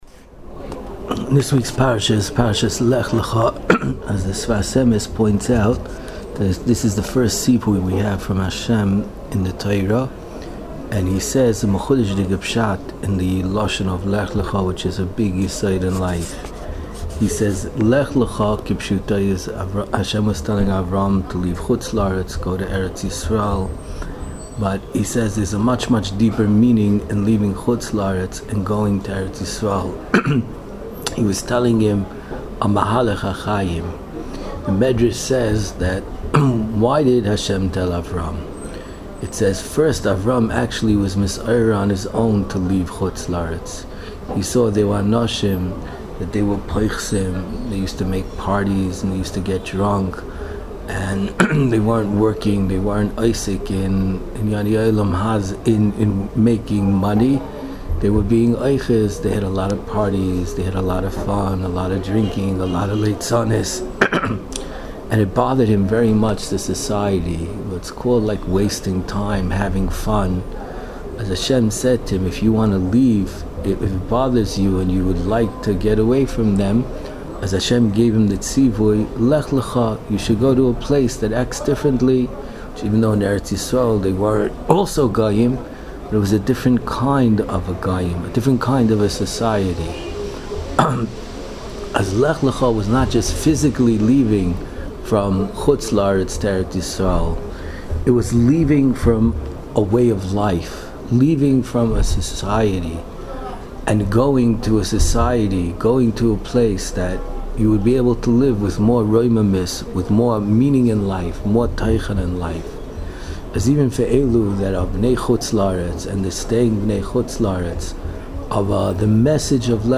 Inspiring Divrei Torah, Shiurim and halacha on Parshas Lech Lecha from the past and present Rebbeim of Yeshivas Mir Yerushalayim.